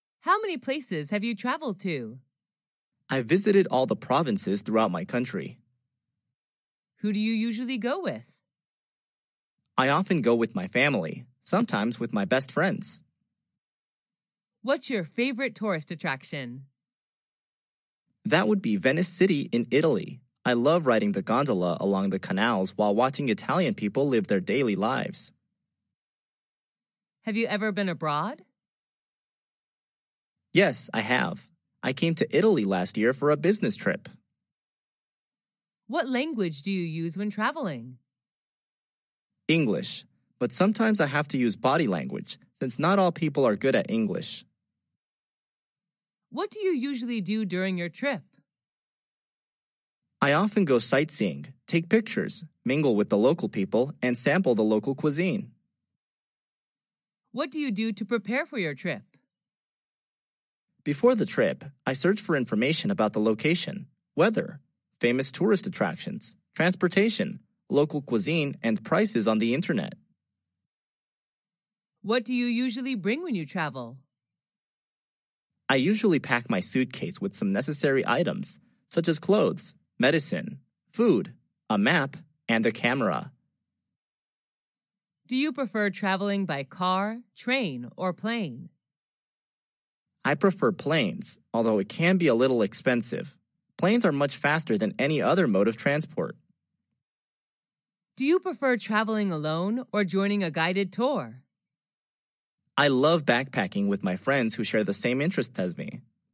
در این درس مکالمه روزمره انگلیسی درباره سفر کردن و مسافرت بین دو دوست را یاد می گیرید.